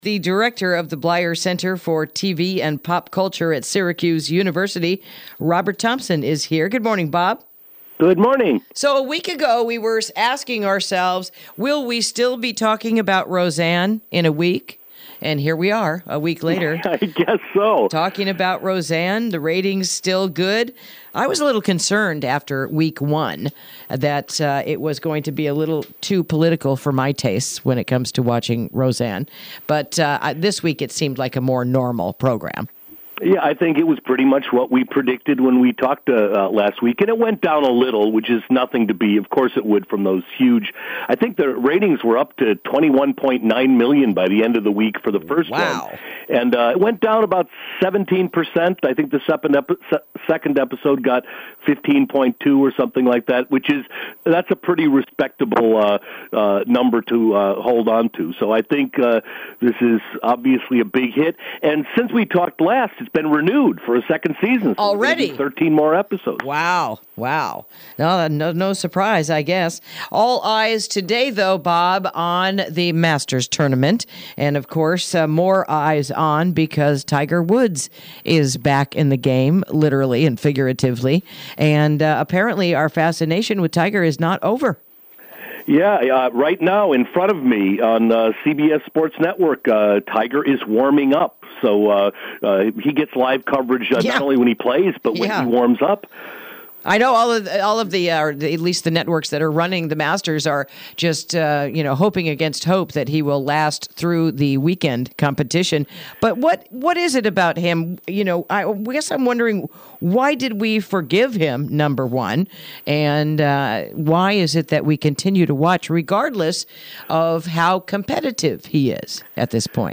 Interview: This Weekend’s Master’s Tournament, Another Royal Child, and “Roseanne” is a Hit!